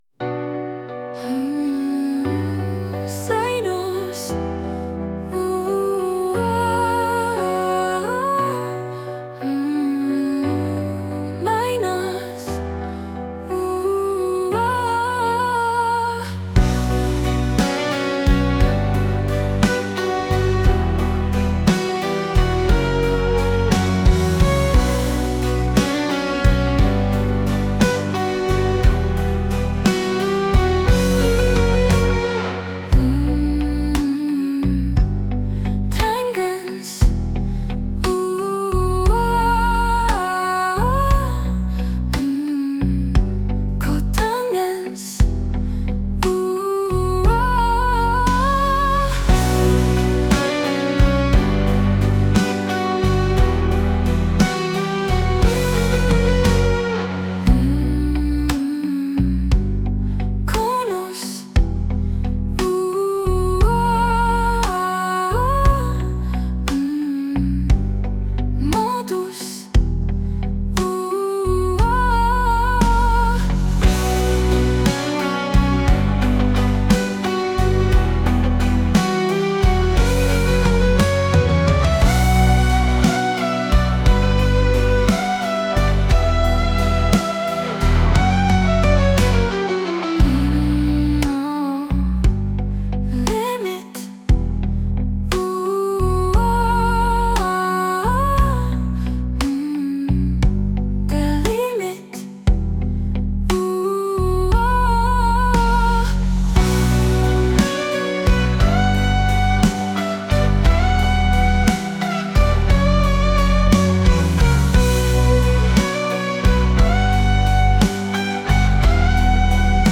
Вокализ с математическими терминами